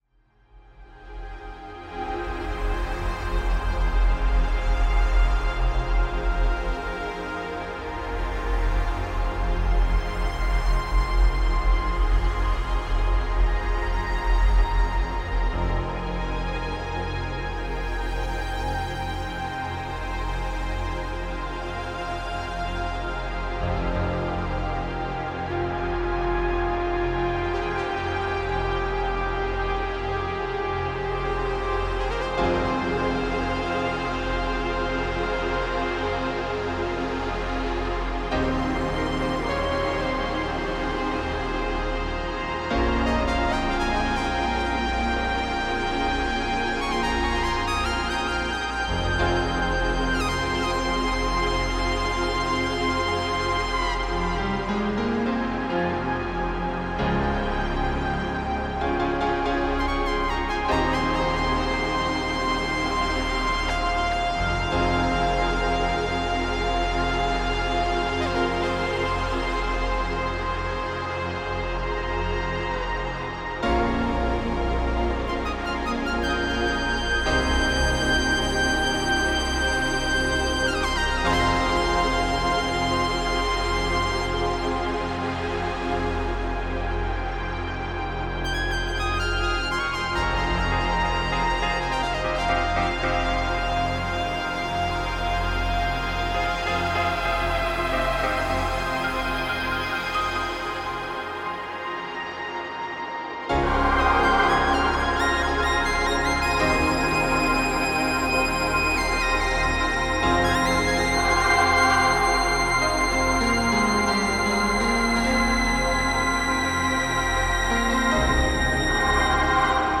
Beats Electronic Hip Hop